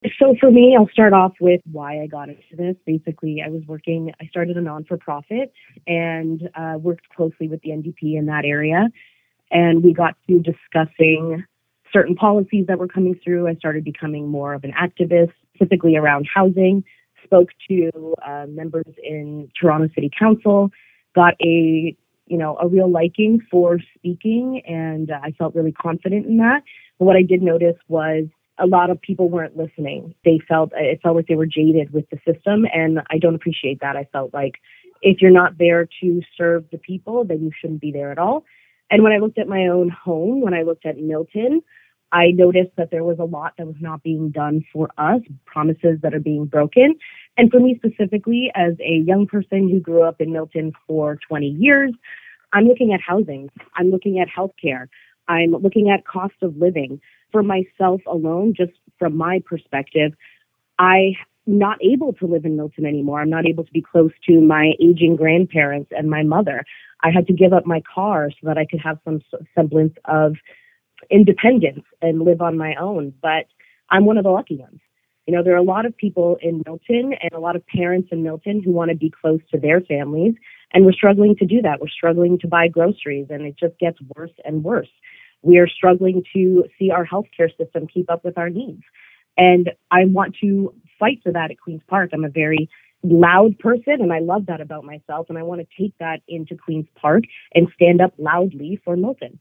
Here’s our interview: